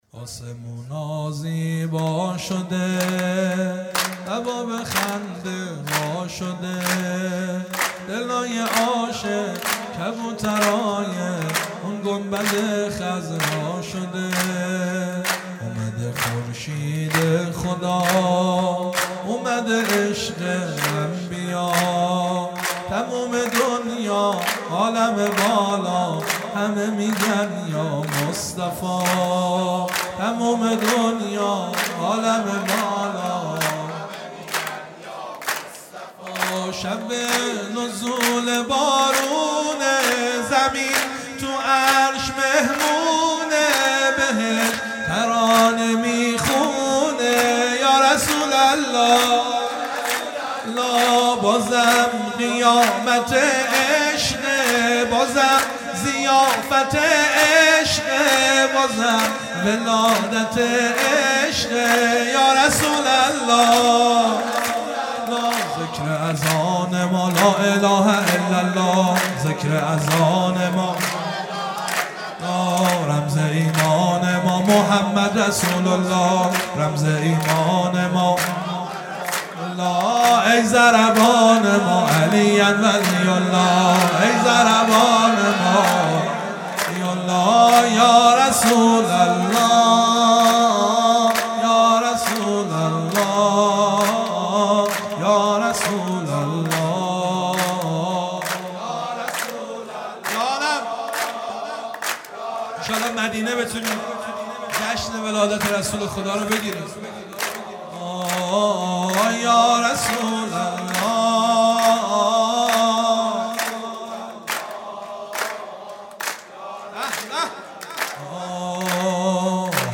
ولادت پیامبر اکرم (ص) | ۲۹ آبان ۱۳۹۷